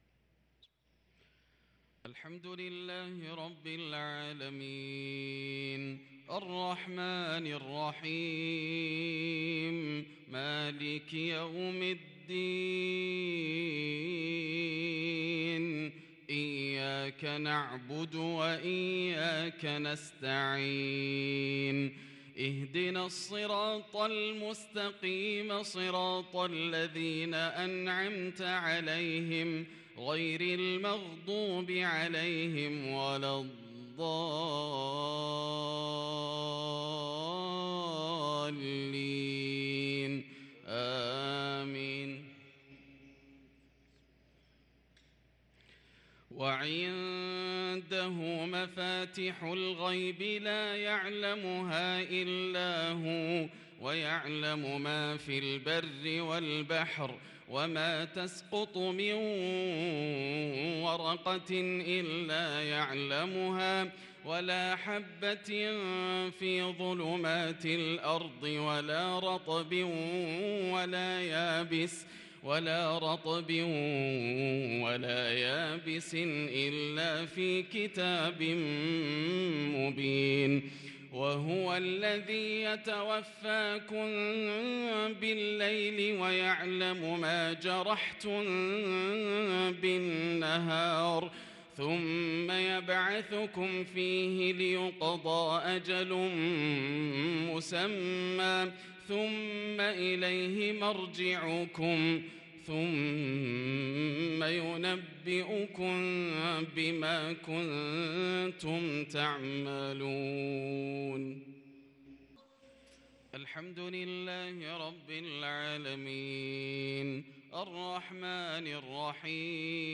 صلاة المغرب للقارئ ياسر الدوسري 5 صفر 1444 هـ
تِلَاوَات الْحَرَمَيْن .